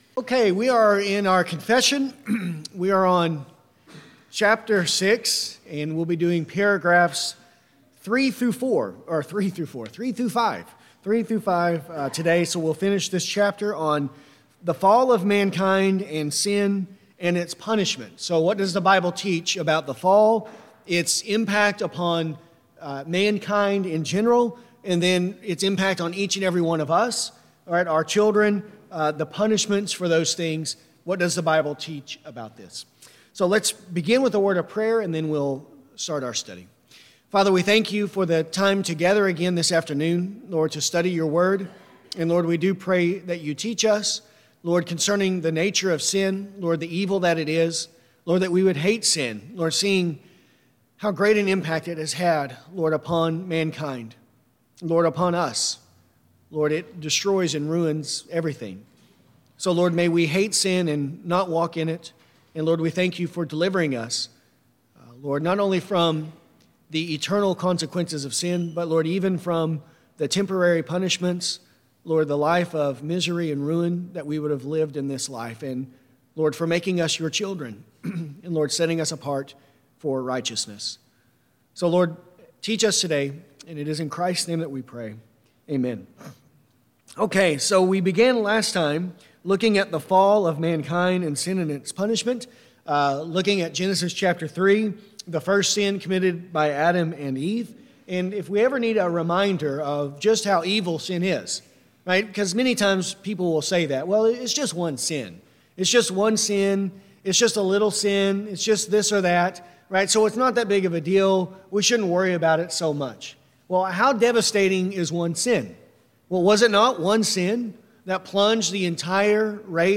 This lesson covers paragraphs 6.3-6.5 .